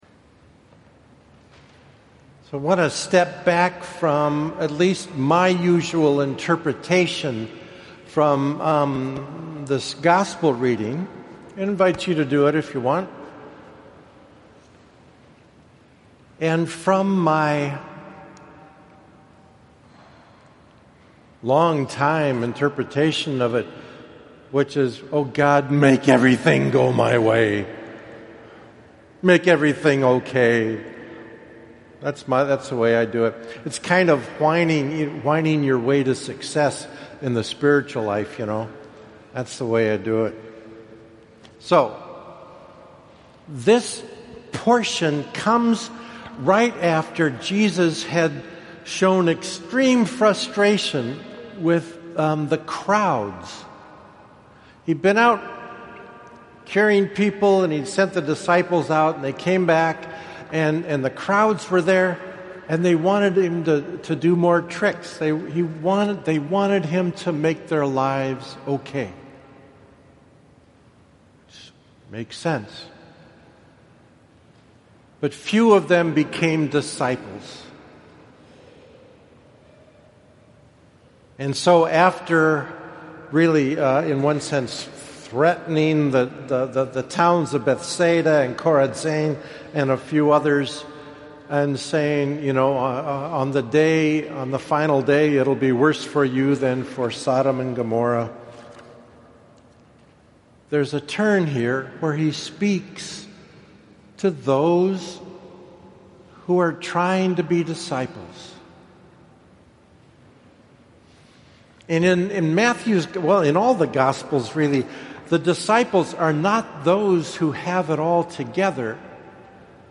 I thought I would post my homily from Sunday today.